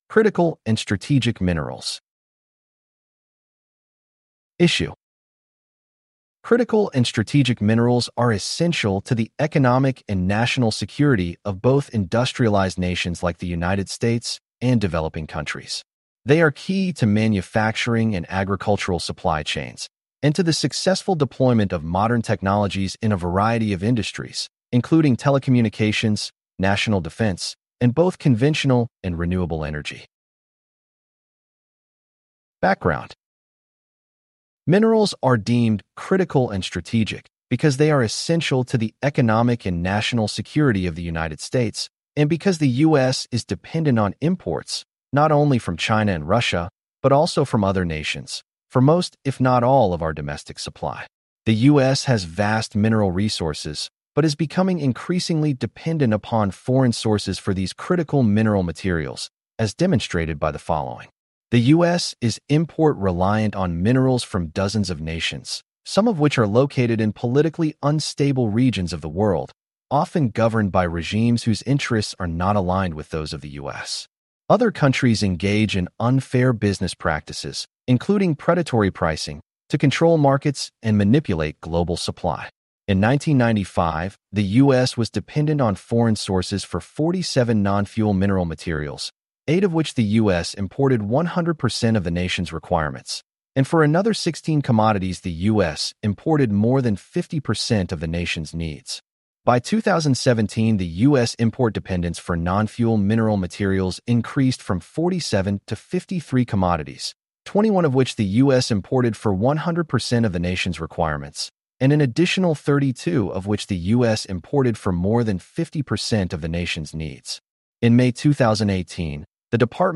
criticalandstrategicmineral-technical-briefing.mp3